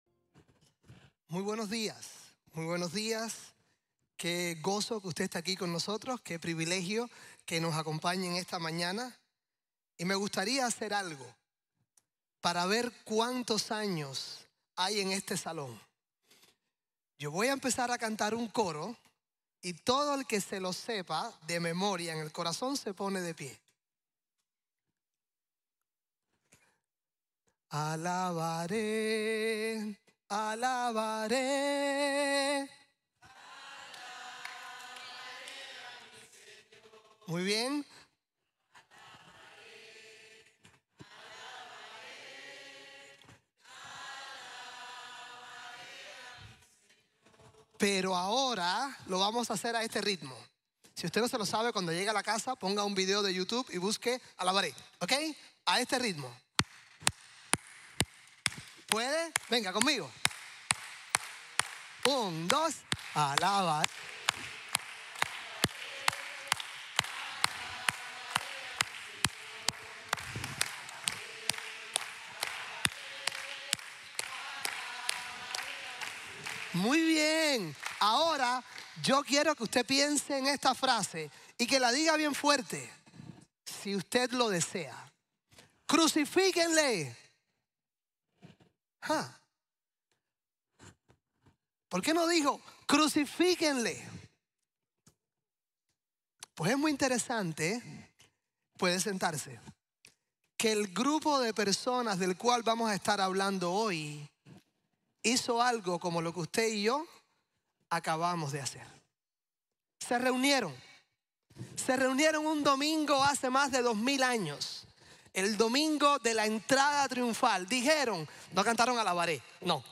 Ven y ve, al Rey de Reyes | Sermon | Grace Bible Church